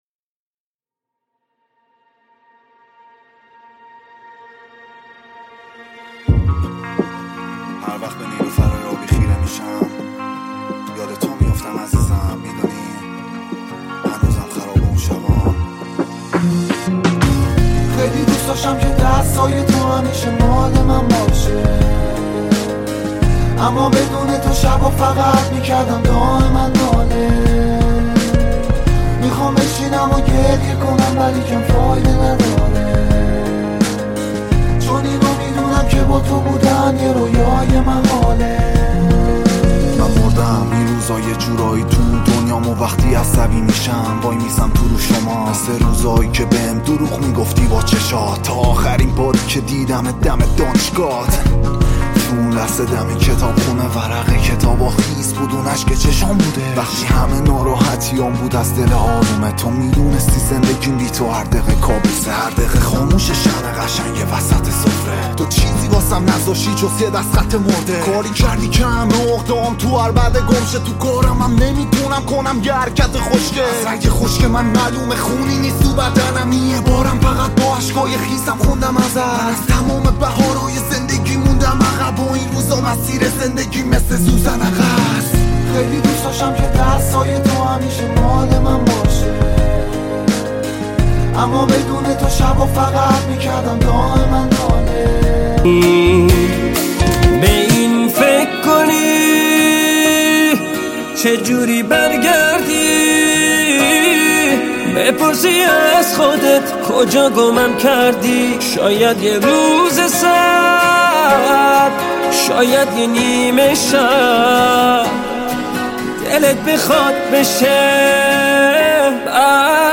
ریمیکس شاد
ریمیکس رپ